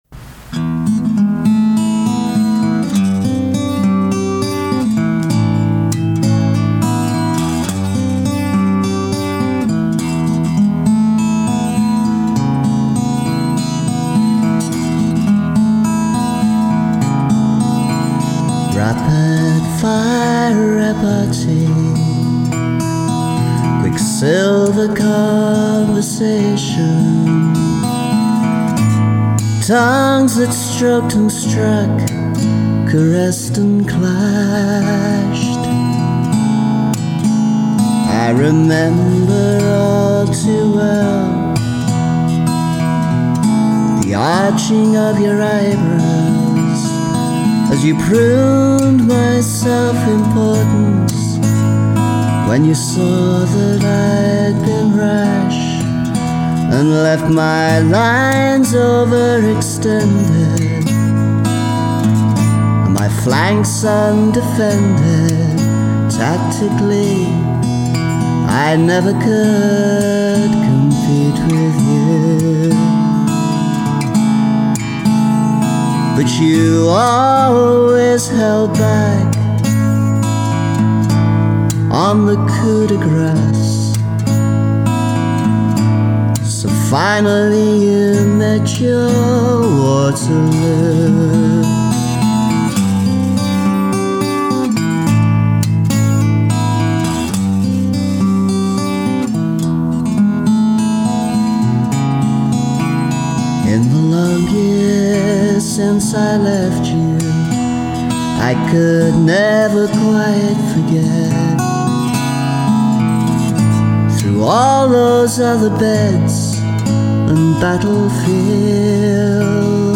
vocals; acoustic/electric/slide guitars.